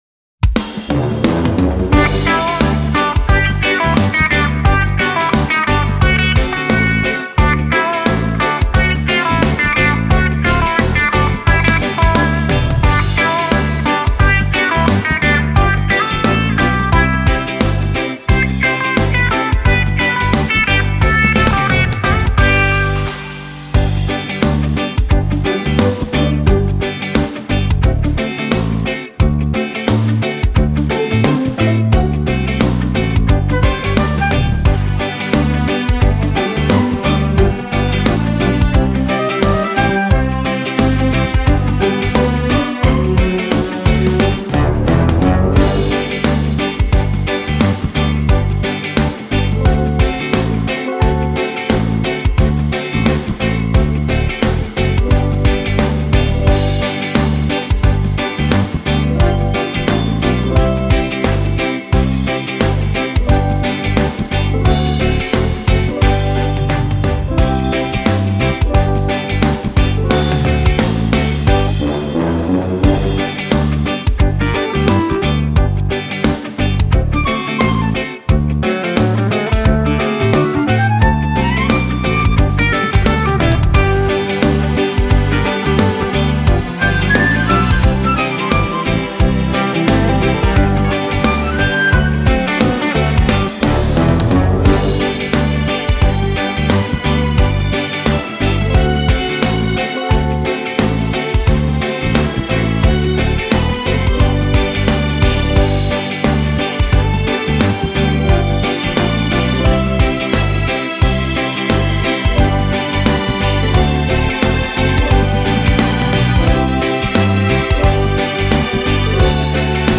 Характер песни: весёлый.
Темп песни: средний.
• Минусовка